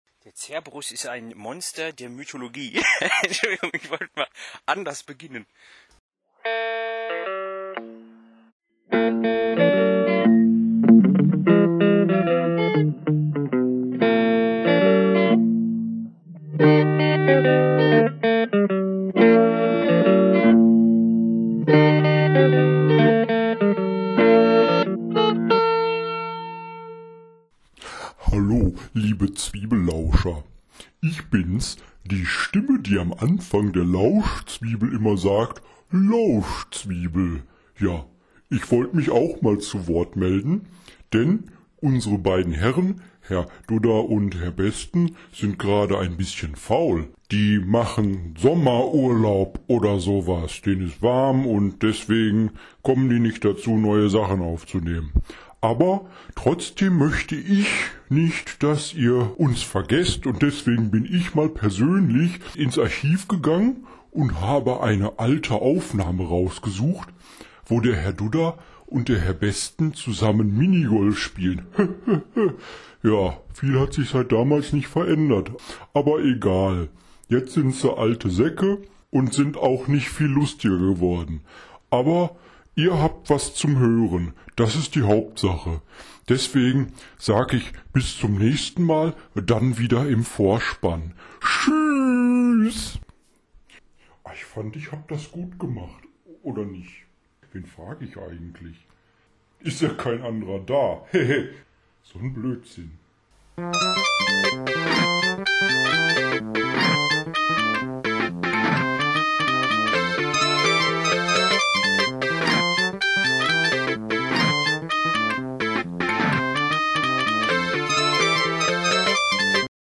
Schon alleine die Tatsache, dass sich beide Herren in diesem Stück Ton-Dokument sportlich betätigen ist eine Weltsensation!